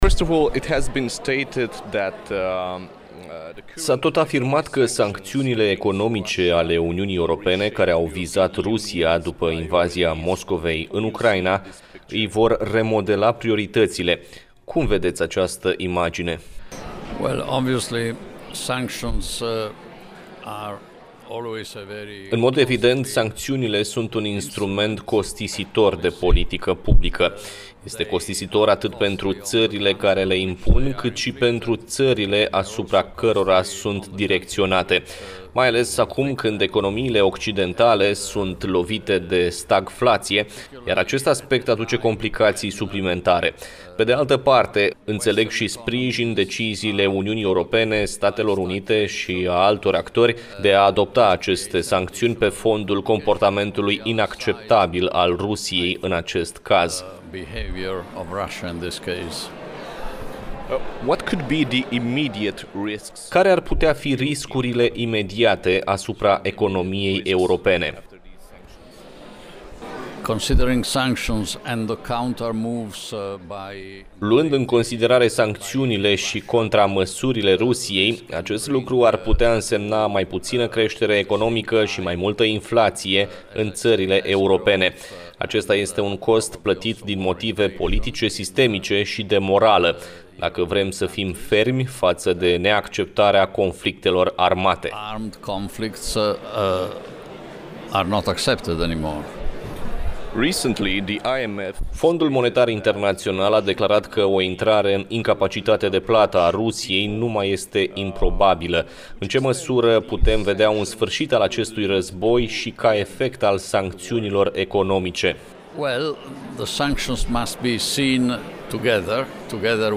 17-martie-Interviu-complet-Mario-Monti.mp3